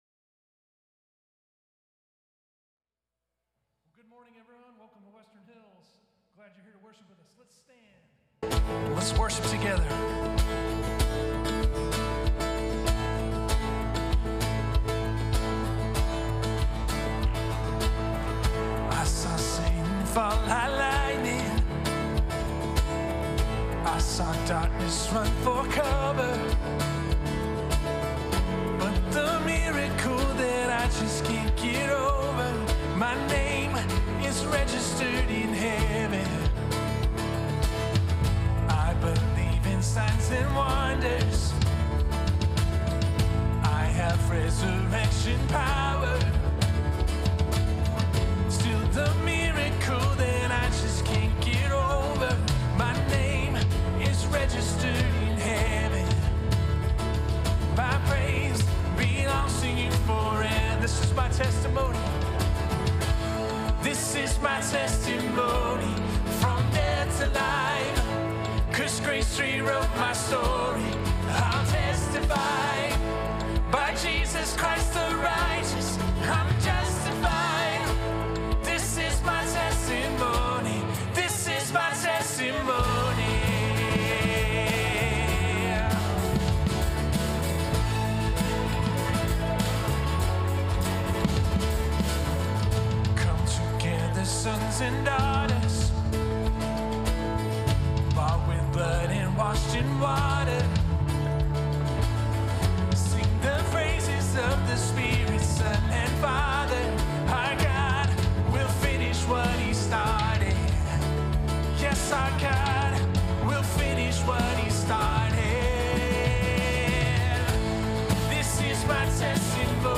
Jun 27, 2022 Green Pastures & Still Waters MP3 Notes Sermons in this Series Pursued to Dwell Shepherd & Host The Valley Paths of Righteousness Green Pastures & Still Waters Songs of Praise, Songs of Lament